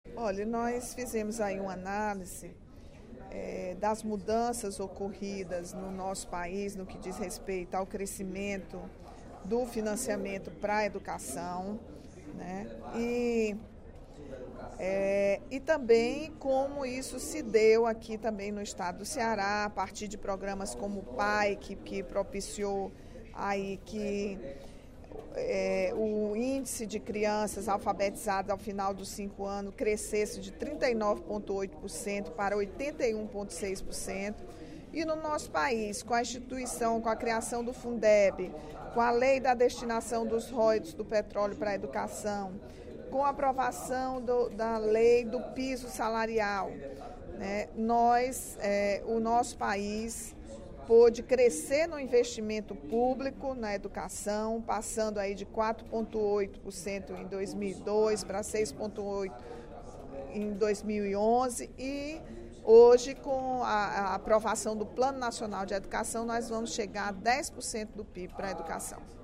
No primeiro expediente da sessão plenária desta quinta-feira (22/05), a deputada Rachel Marques (PT) destacou o primeiro encontro do Ciclo de Debates da Educação - Pacto Federativo, Regime de Colaboração e Financiamento da Educação Nacional: limites e possibilidades para a valorização dos profissionais de educação.